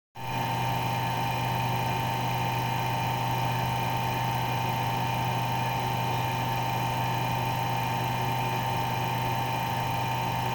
电机.wav